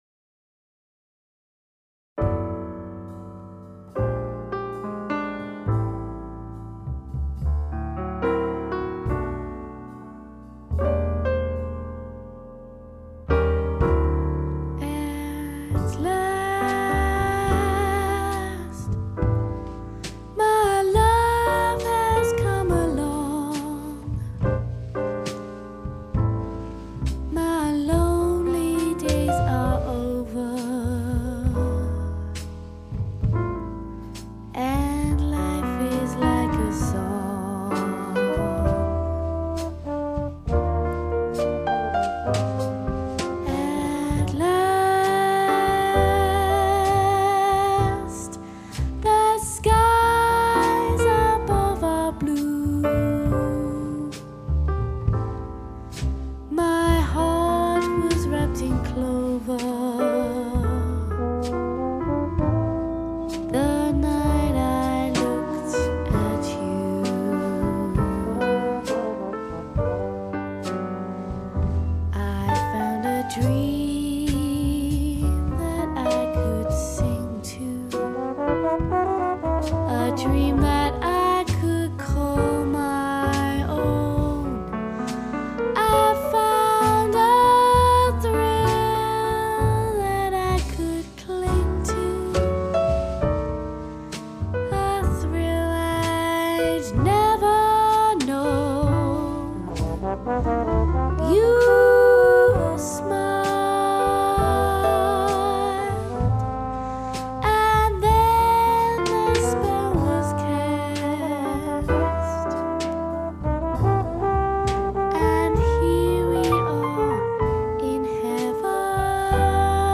vocals / sax / trumpet / piano / bass / drums
A great mix of jazz, latin, swing, funk and soul.